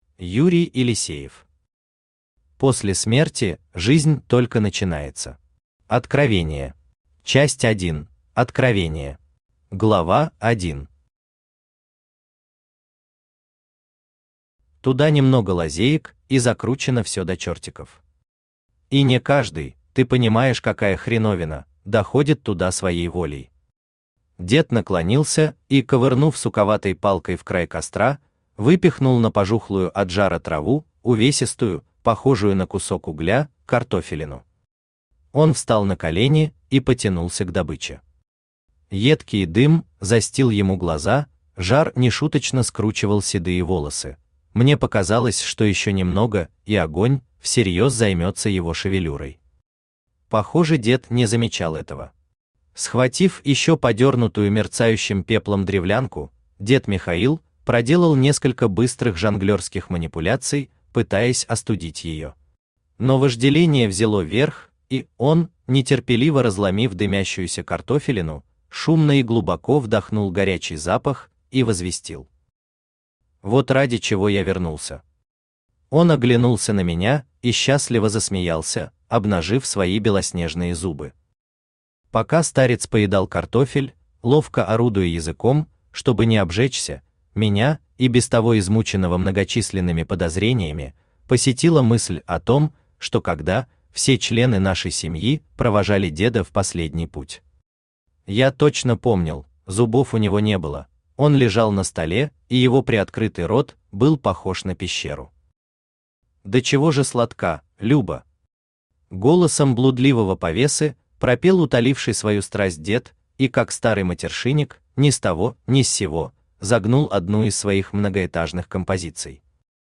Откровение Автор Юрий Павлович Елисеев Читает аудиокнигу Авточтец ЛитРес.